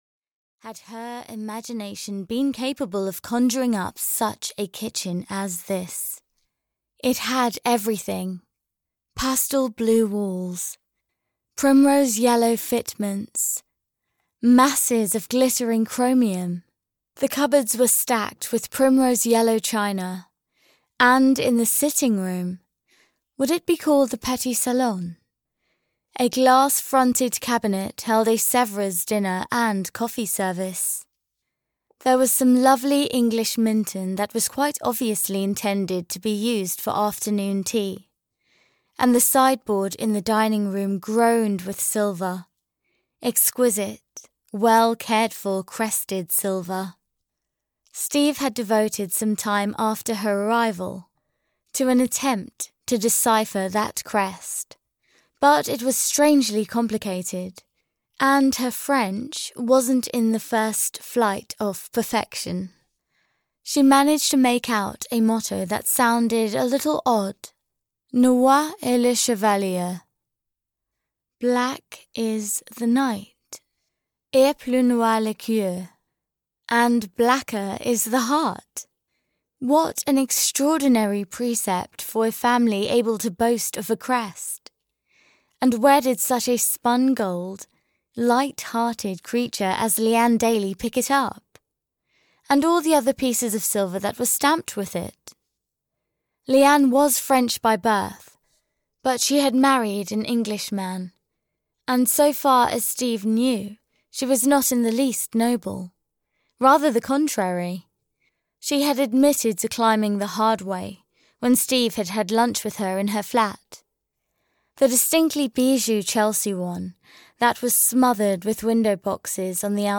Moon at the Full (EN) audiokniha
Ukázka z knihy